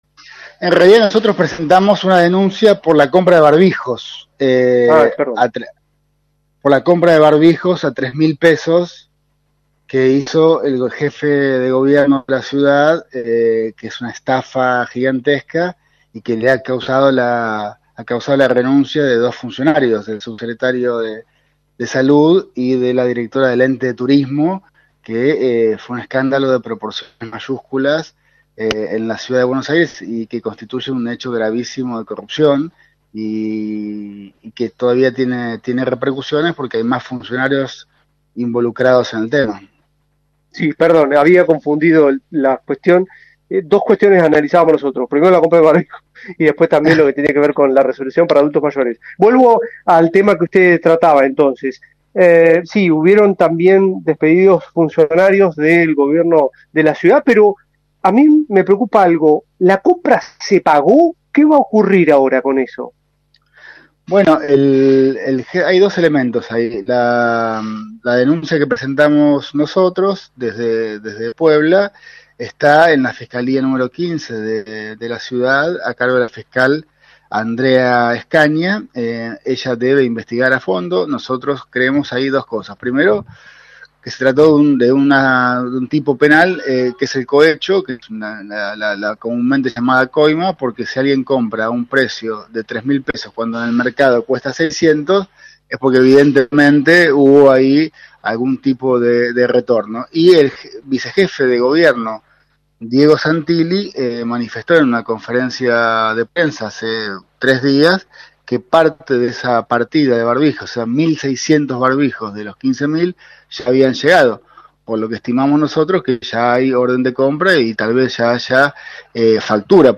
Compra de barbijos con sobreprecio en CABA: entrevista a José Cruz Campagnoli – Radio Universidad
Radio Universidad de La Plata habló hoy con José Cruz Campagnoli, ex diputado en la legislatura de CABA integrante de Puebla Fuerza Colectiva, acerca de la denuncia que presentaron por la compra de barbijos por parte del Gobierno de la Ciudad de Buenos Aires.